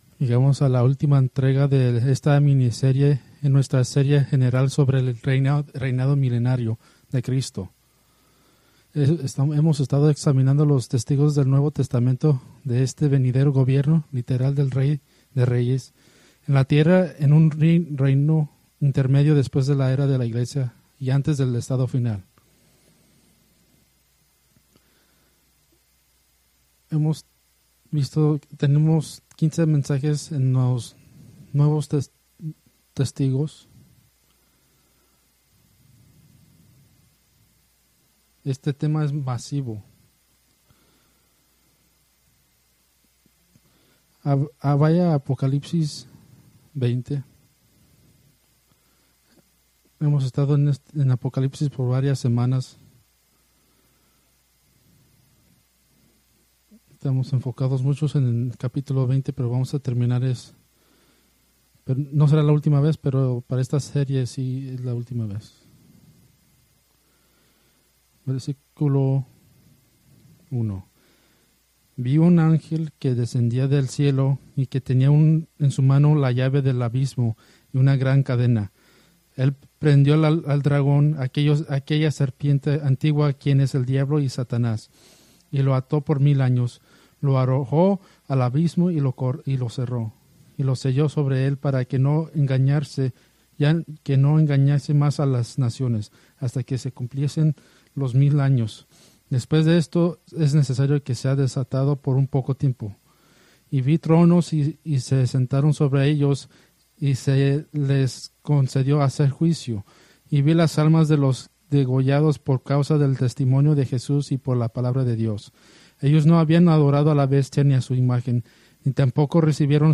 Preached February 23, 2025 from Escrituras seleccionadas